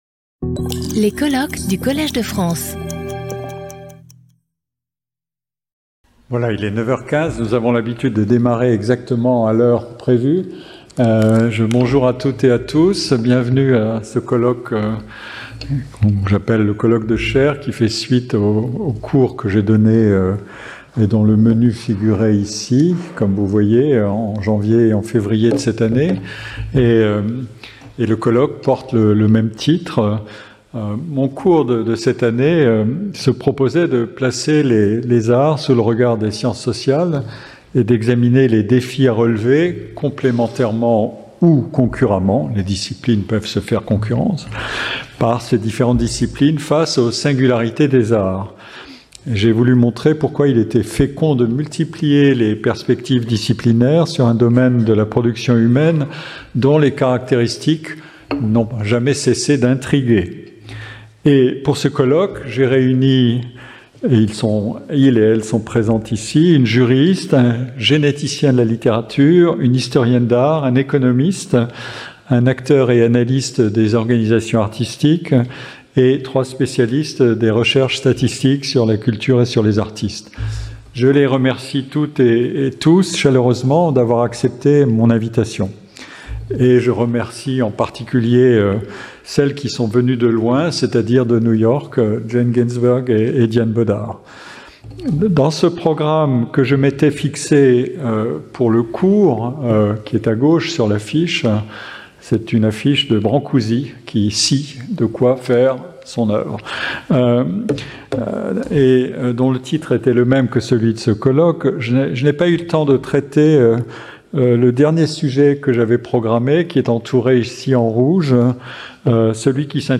Pierre-Michel Menger Professeur du Collège de France
Colloque